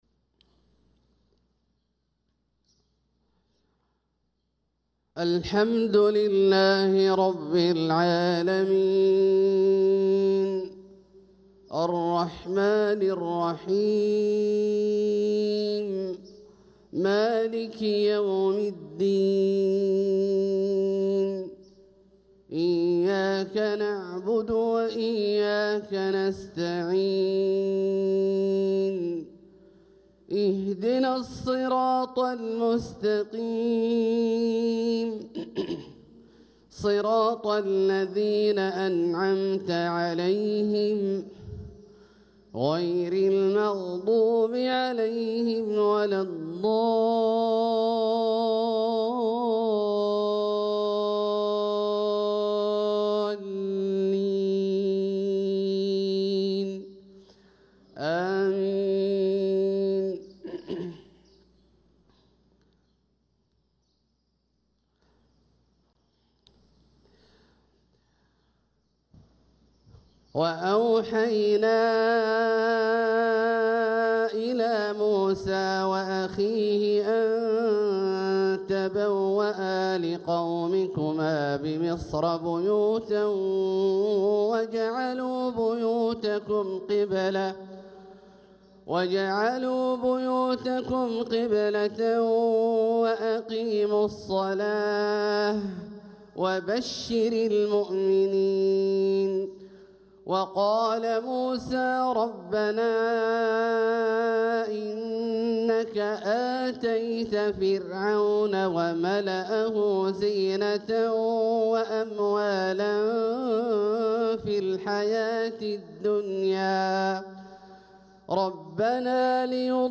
صلاة الفجر للقارئ عبدالله الجهني 4 جمادي الأول 1446 هـ
تِلَاوَات الْحَرَمَيْن .